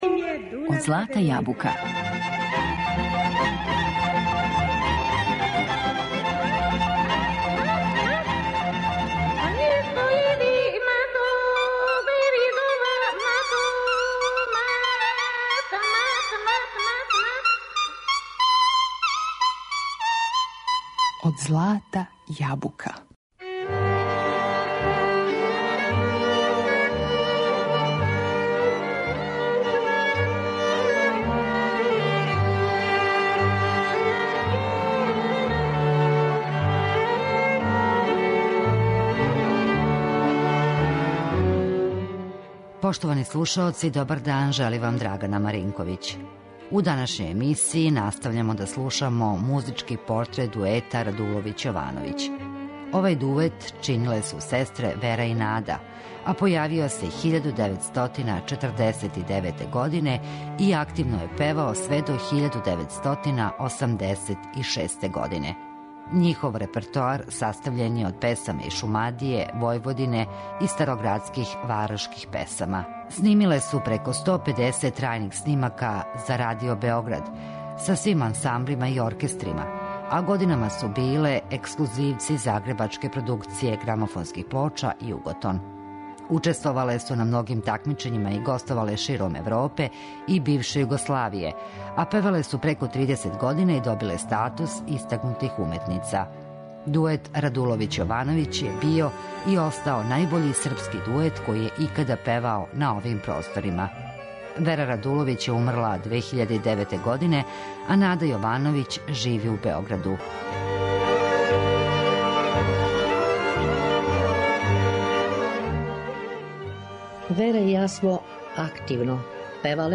Појавиле су се 1949. године, а њихов репертоар чиниле су песме из Шумадије, Војводине и градске песме. Снимиле су преко сто педесет трајних снимака за Тонски архив Радио Београда, са свим ансамблима и оркестрима.